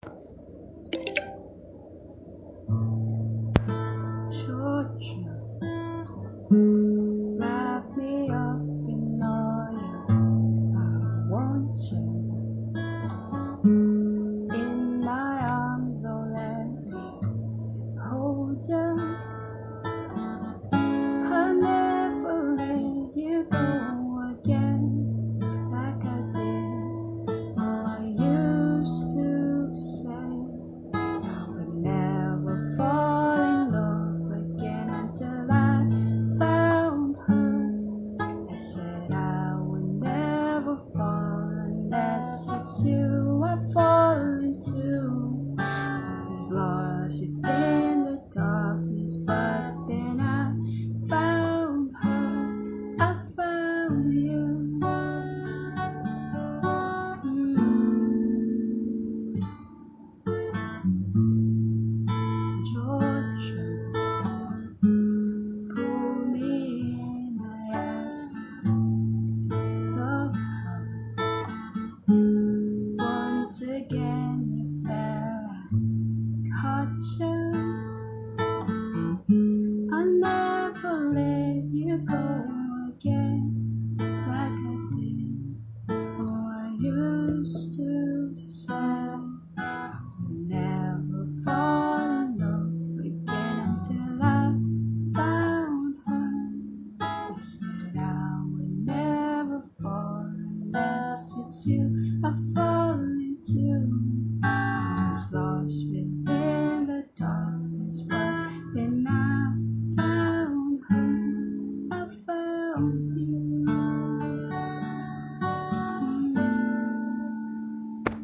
on my phone